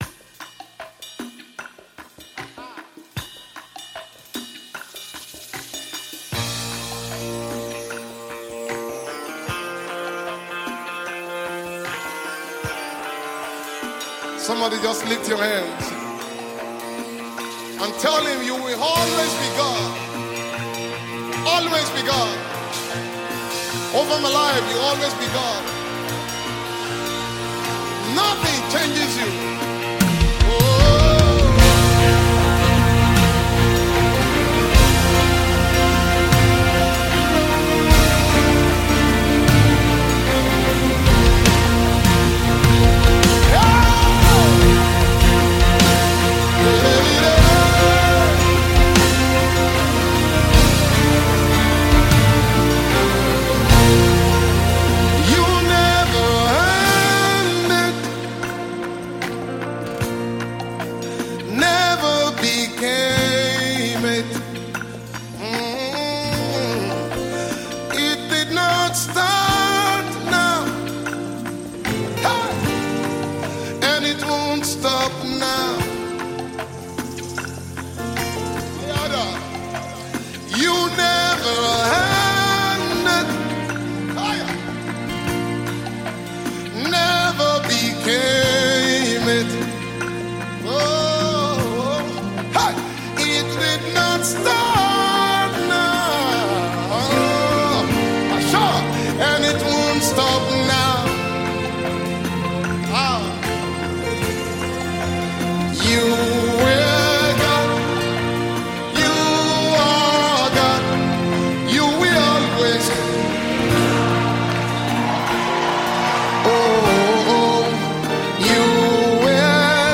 worship-rendering song